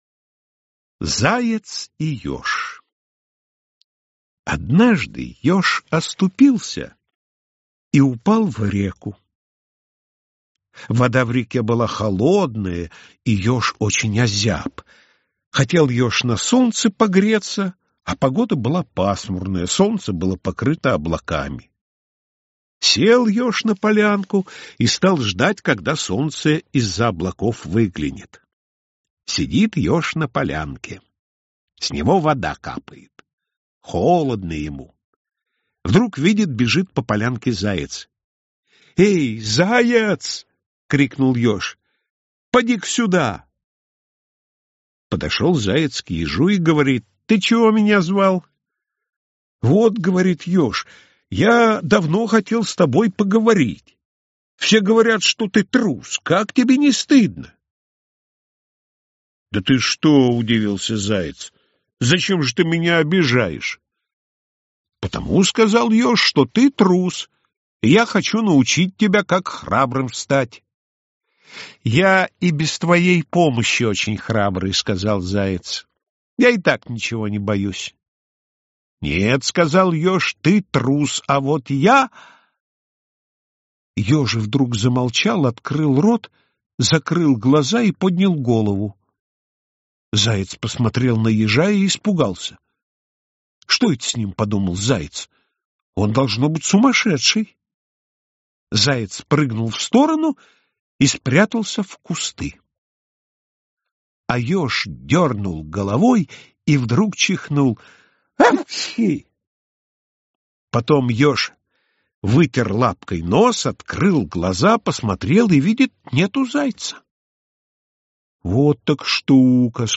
Аудиосказка «Заяц и ёж»